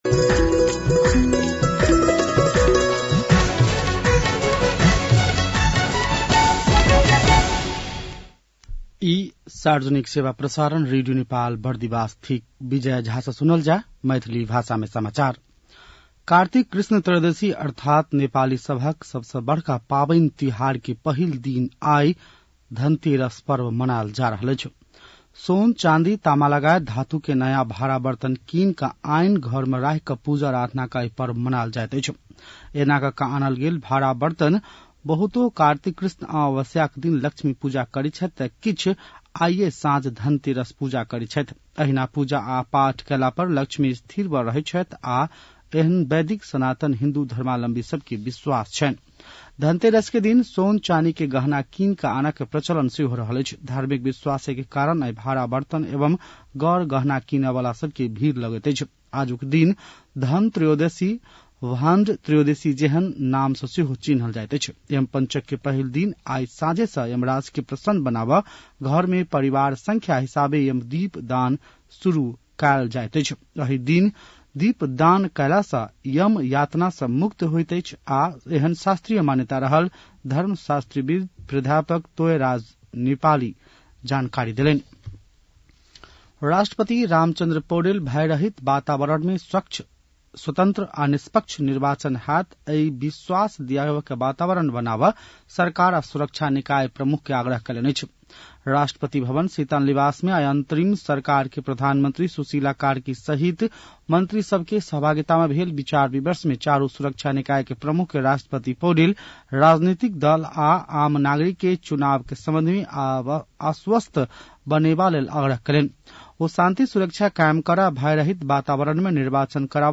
An online outlet of Nepal's national radio broadcaster
मैथिली भाषामा समाचार : १ कार्तिक , २०८२